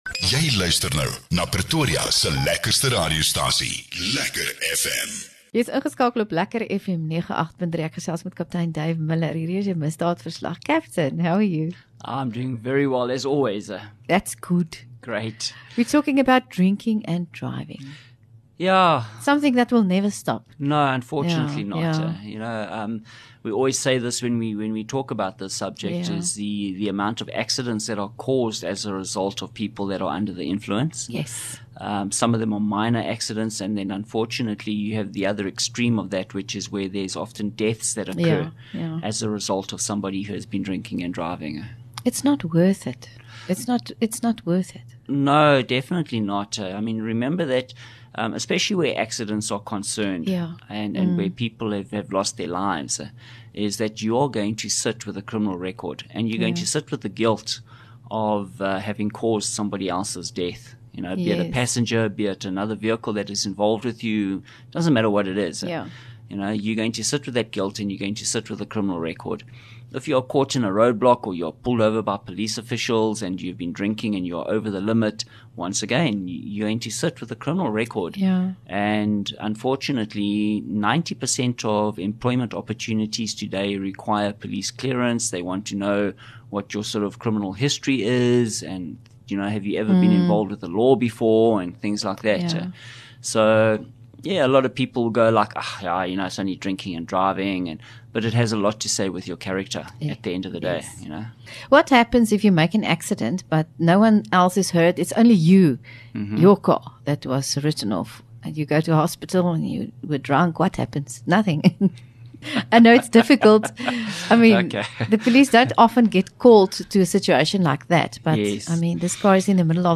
LEKKER FM | Onderhoude 4 Jul Misdaadverslag